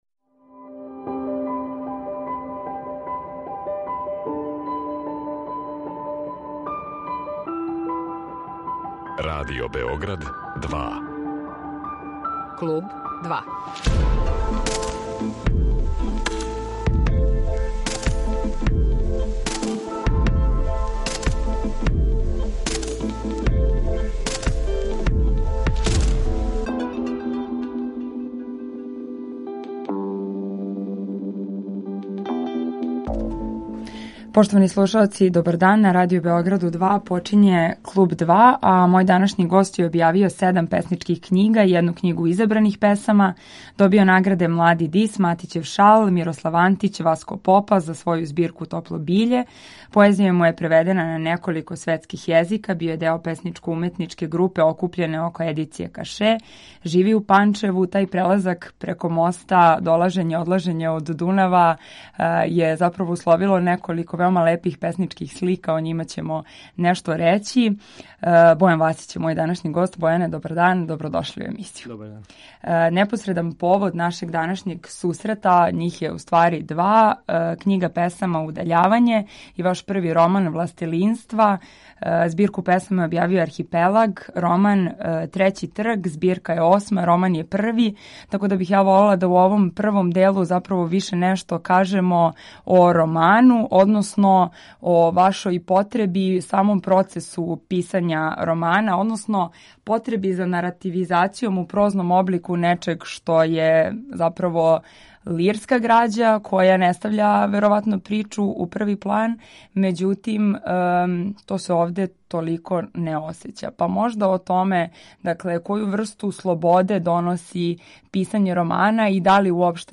Неколико је важних повода за разговор са нашим данашњим гостом, а пре свега, то су ‒ нова песничка књига „Удаљавање", али и први роман „Властелинства".